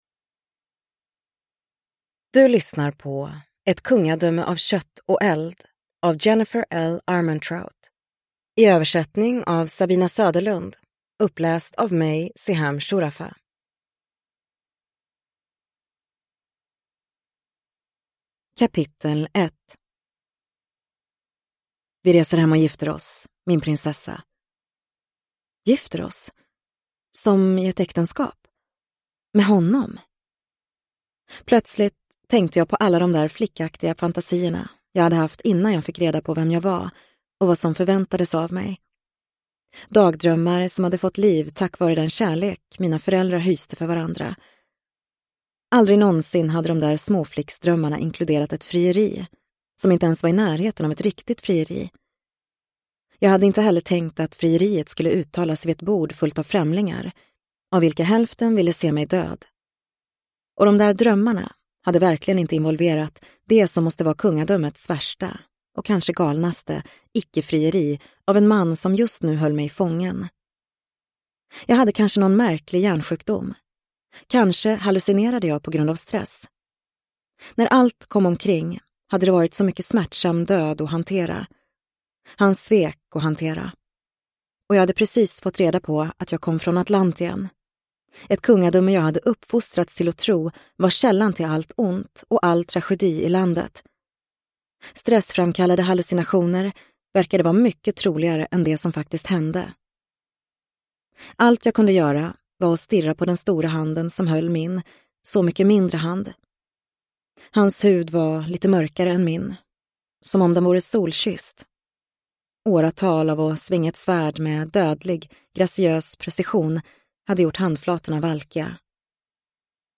Ett kungadöme av kött och eld – Ljudbok – Laddas ner